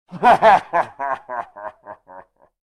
chortle.ogg